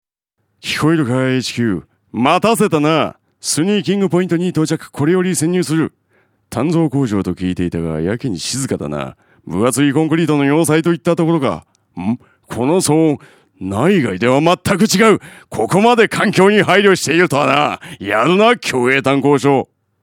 ラジオCM制作
有名アニメキャラクターを彷彿させる語り口で、壮大な世界観をラジオCMで表現。